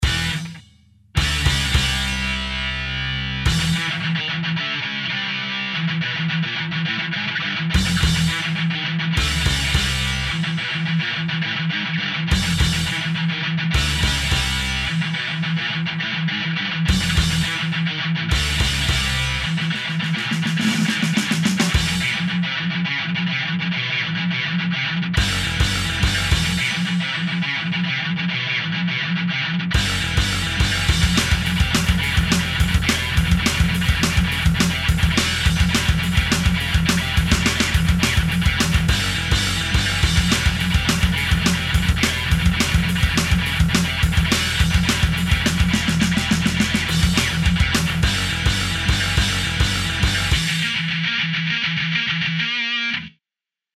подобрать подходящего к миксу железа в бфд2 я не смог.